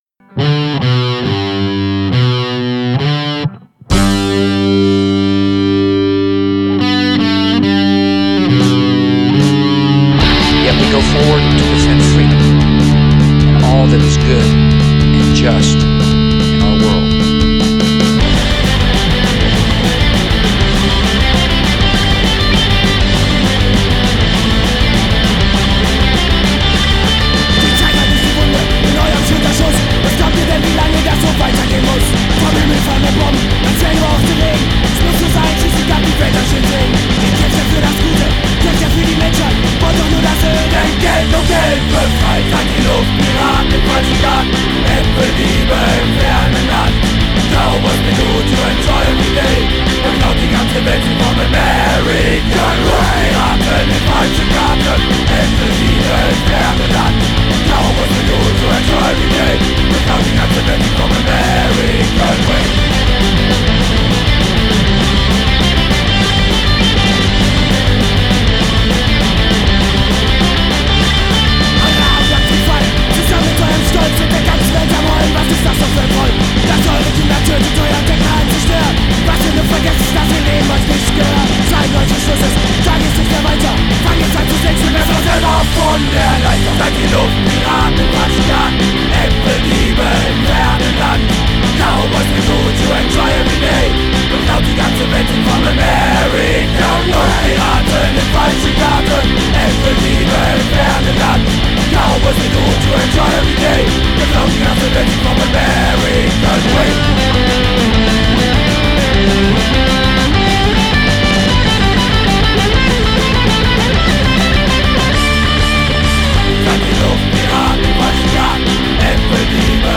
Alte Demoaufnahmen: